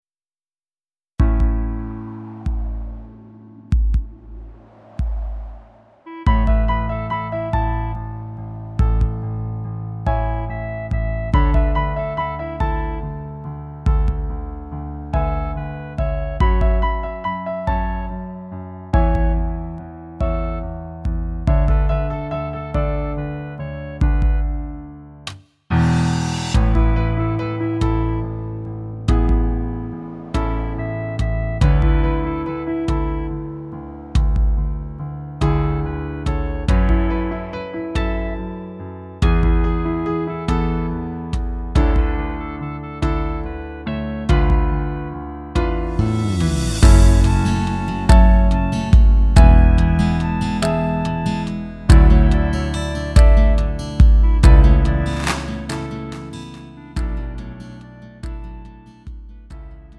음정 원키 3:47
장르 가요 구분